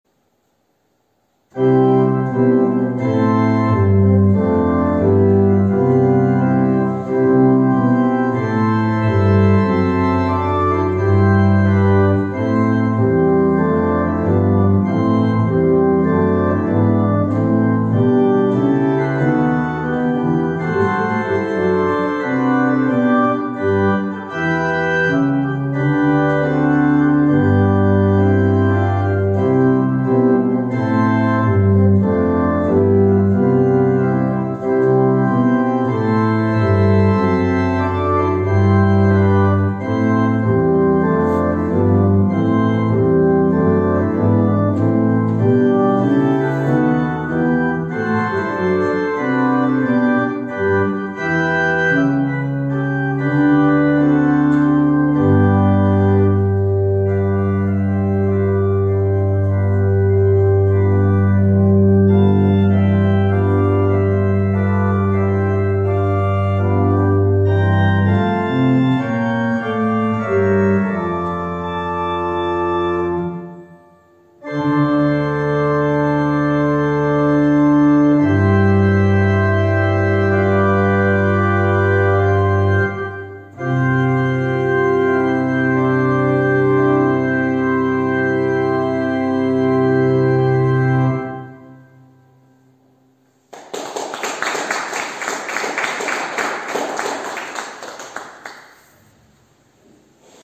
Gottesdienst aus der reformierten Erlöserkirche, Wien-Favoriten, am 23.
Orgelvorspiel